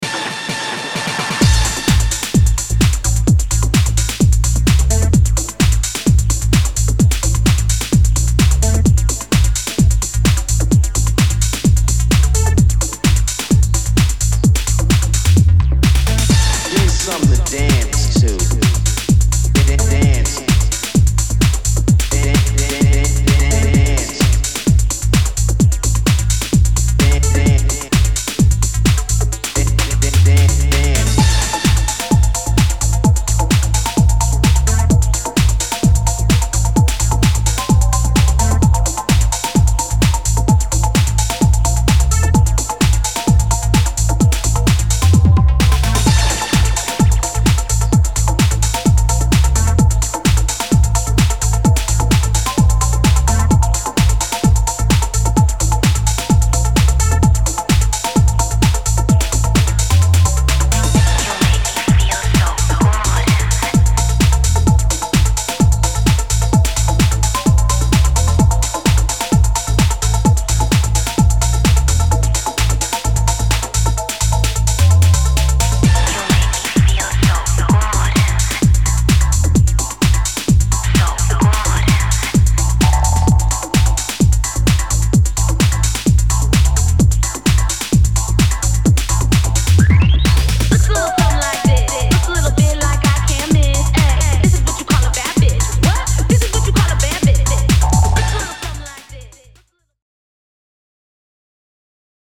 強めなアタックのグルーヴにレイヴィーなスタブ、物憂げなシンセを配した